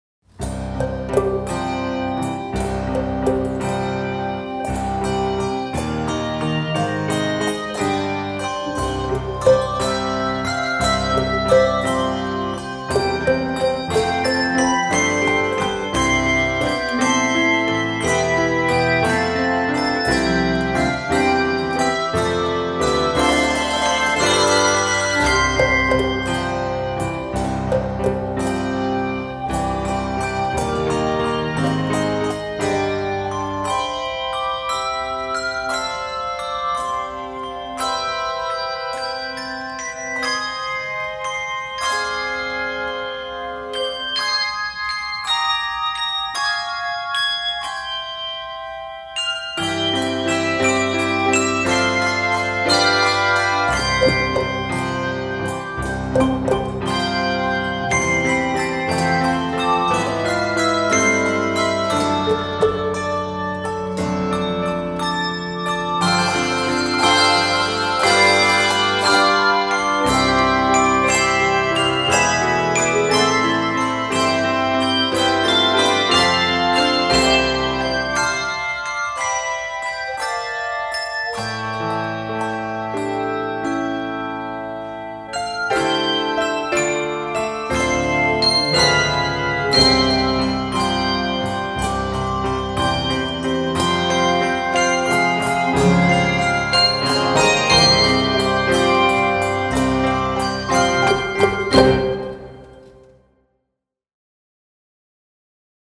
this lively melody
handbell piece